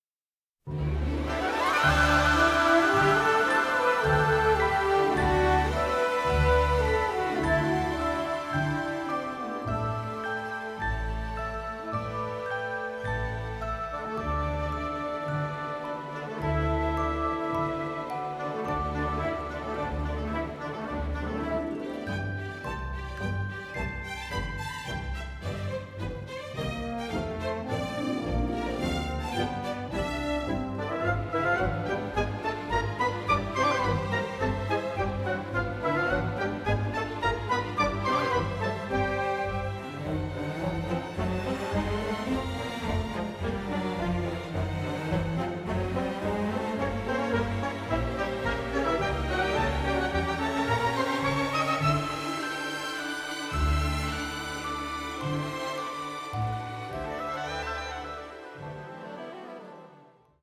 Recorded at CTS Studios in London